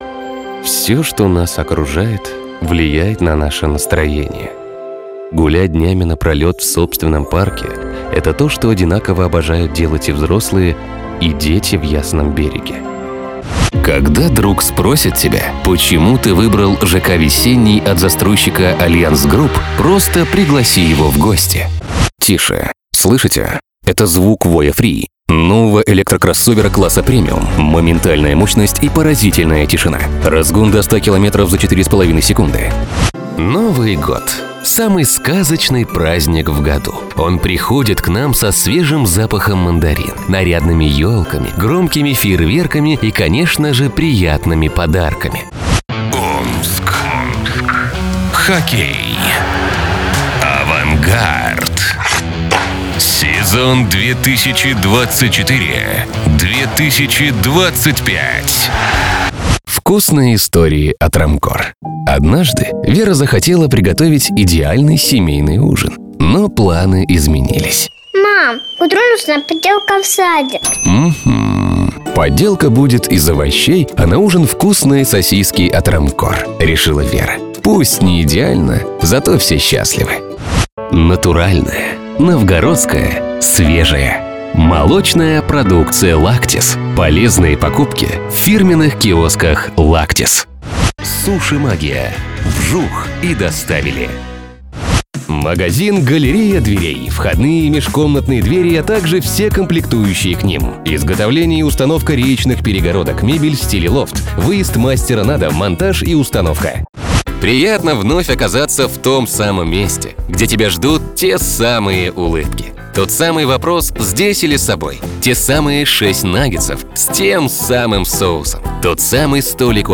Пример звучания голоса
ARK TUBE, RODE K-2 ; RODENT USB звуковая карта: MOTU M4, преамп: Long Stereo Channel